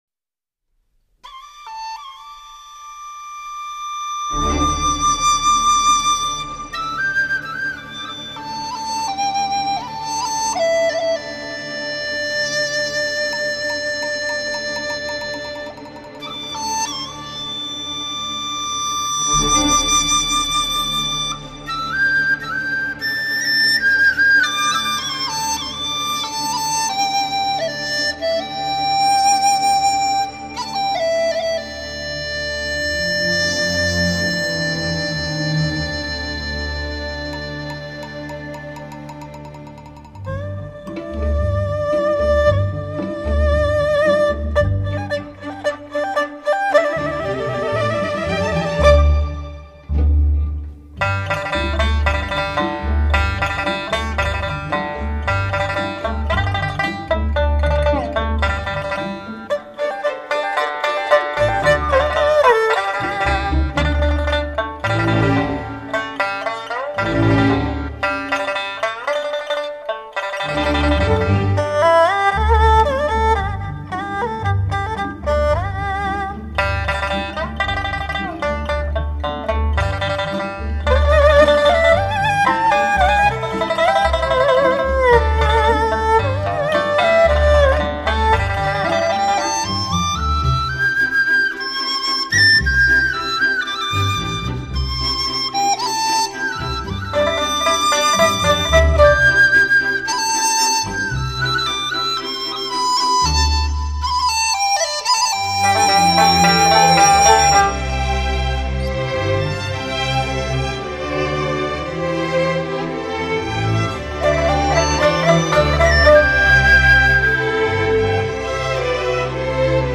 充满情感和灵感的声音。
激情四溢的动人演奏，清新淡雅的音乐气息，如潺潺流水之声，自然古朴，源远流长，美妙纯净之音不绝于耳！
乐队以中国民族乐器为主奏和领奏辅以西洋管弦乐器及电声乐器，采用最新数码录音系统，高科技HDCD音效处理录制。
美妙的现代民族音乐